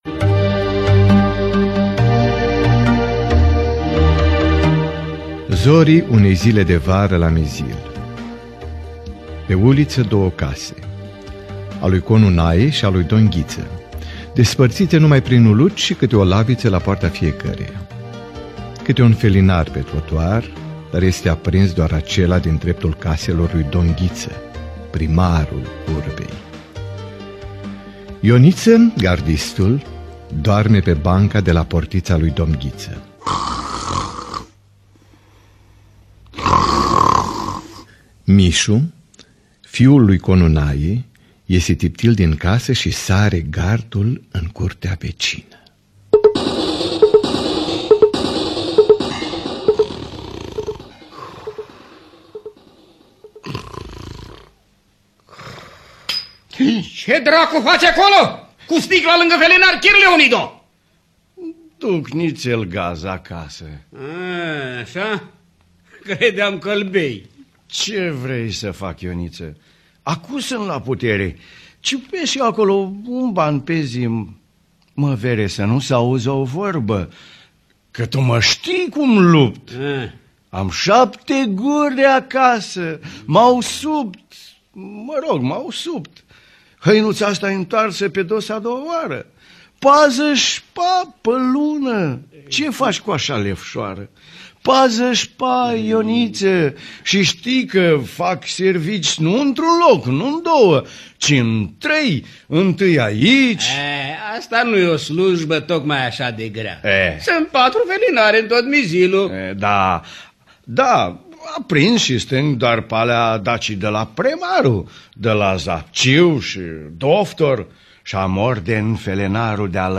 Romeo și Julieta la Mizil de George Ranetti – Teatru Radiofonic Online